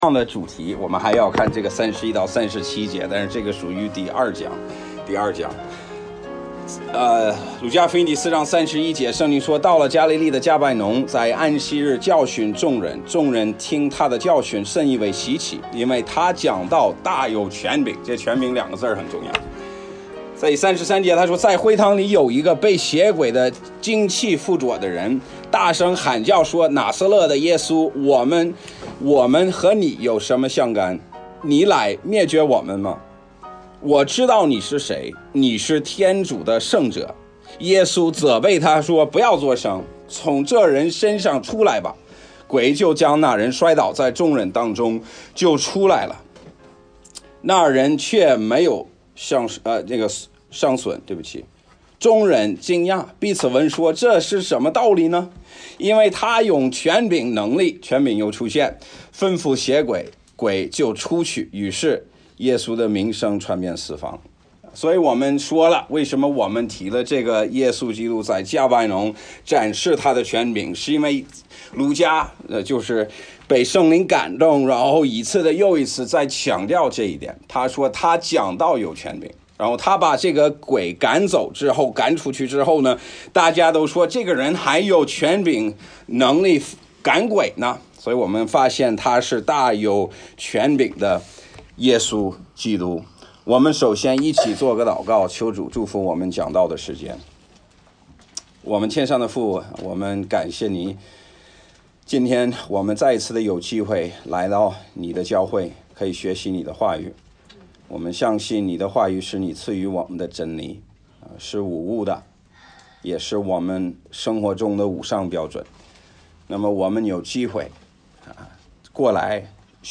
Bible Text: 路加福音4章31-37节 | 讲道者